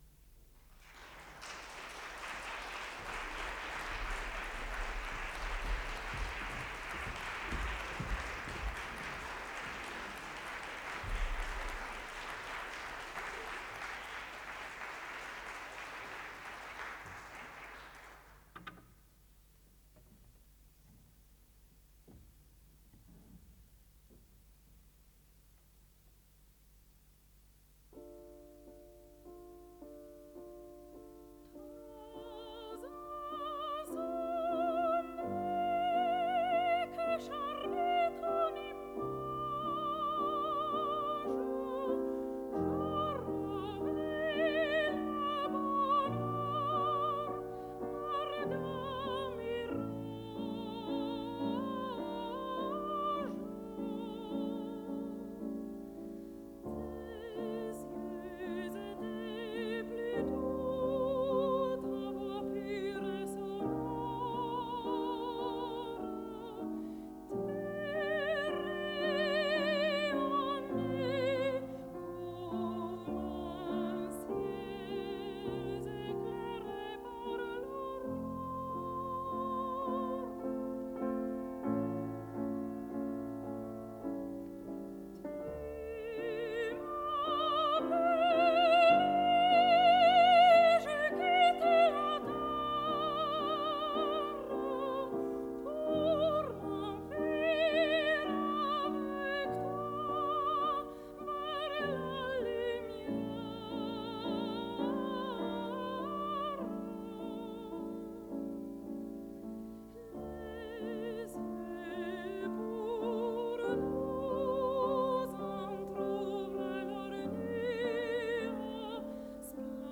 musiikkiäänite
Soitinnus: lauluääni (sopraano), piano.
Sibelius-Akatemian oppilasvaihtokonsertti Kölnin konservatoriossa 19.11.1976.
Äänitetty: 19.11.1976, Köln, Musikhochschule (konserttitaltiointi).